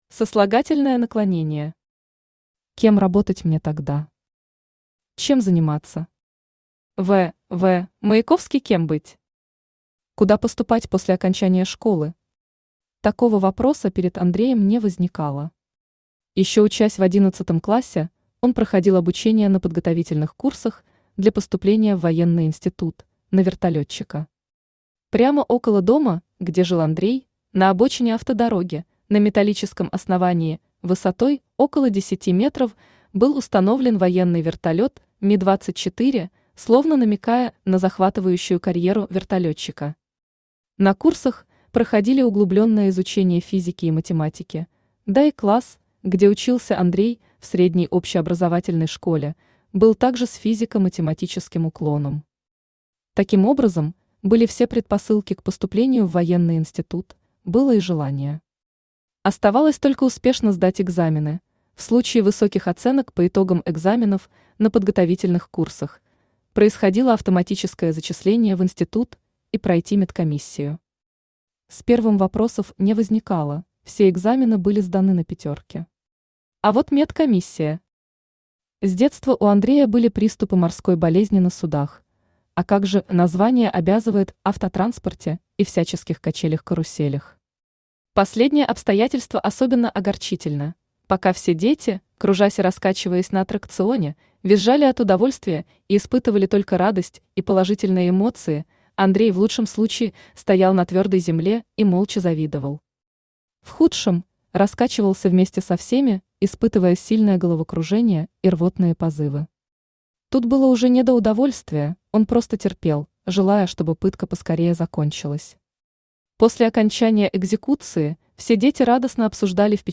Аудиокнига Микромемуары мудрого майора | Библиотека аудиокниг
Aудиокнига Микромемуары мудрого майора Автор Андрей Евгеньевич Лукаш Читает аудиокнигу Авточтец ЛитРес.